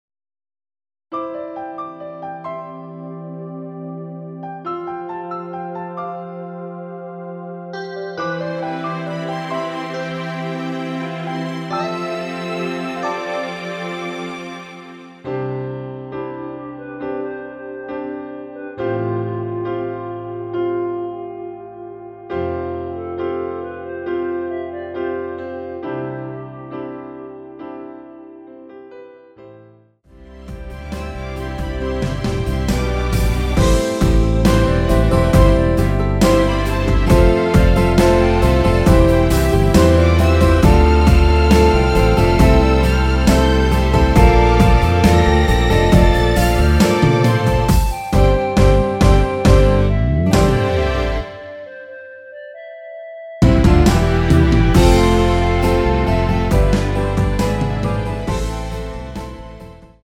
원키에서(-6)내린 멜로디 포함된 MR입니다.(미리듣기 참조)
앞부분30초, 뒷부분30초씩 편집해서 올려 드리고 있습니다.
중간에 음이 끈어지고 다시 나오는 이유는